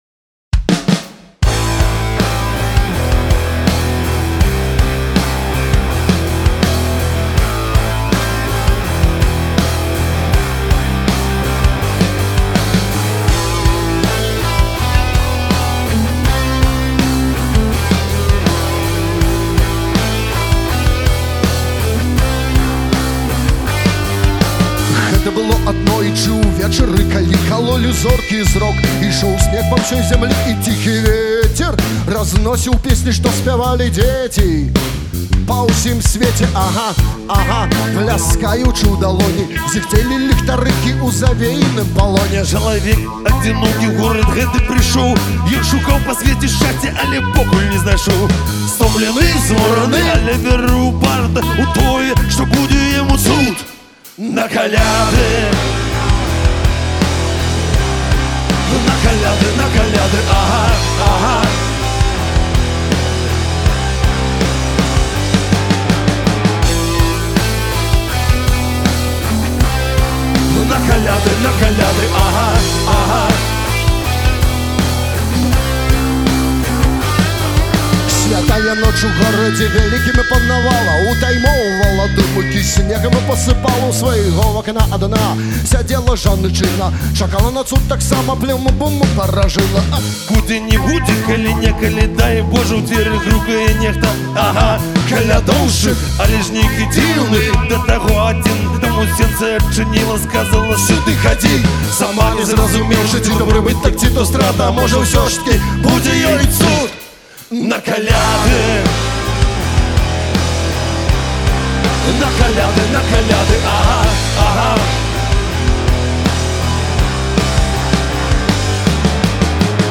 Знакамітая рэп-калядка ў новым прачытаньні